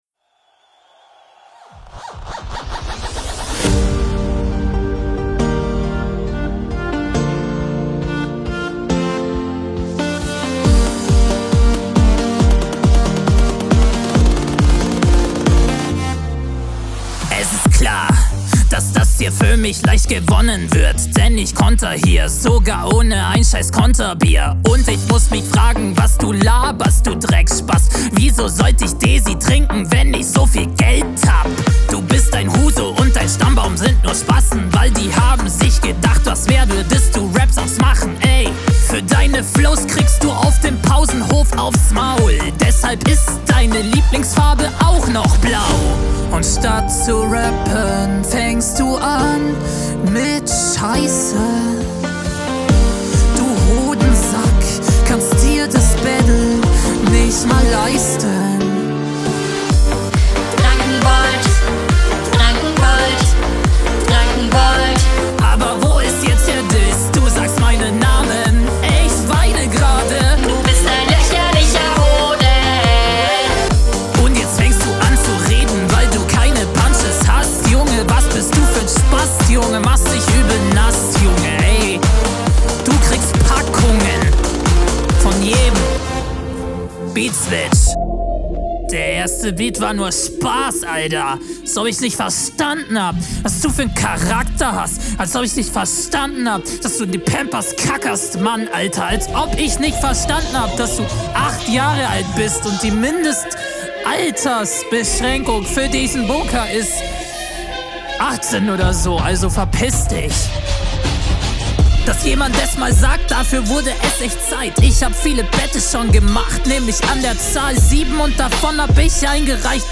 Ohne witz klingt 1:1 wie ein Ballermannsong.